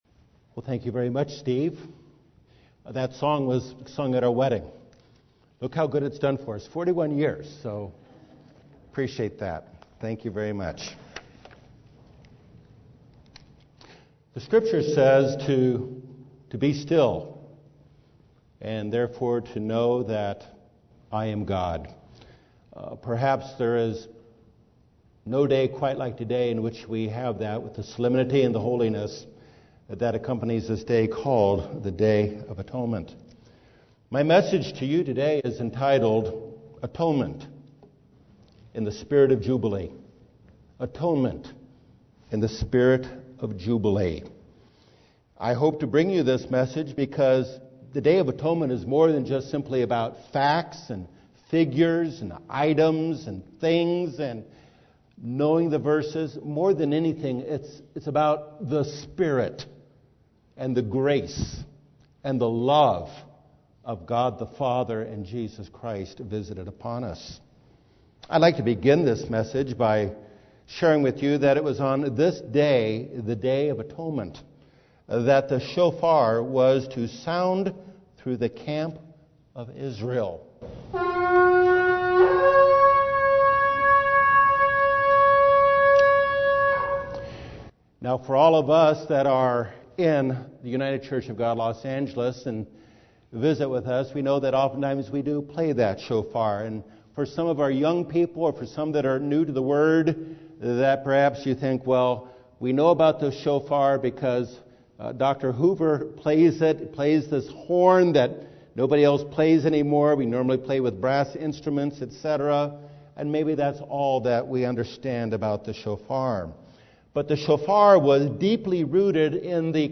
This message, given on the Day Atonement, examines the significance of the day and explores the importance of the Year of Jubilee, initiated on the Day of Atonement every 50th year, proclaiming liberty throughout the land to all of its inhabitants.
UCG Sermon Transcript This transcript was generated by AI and may contain errors.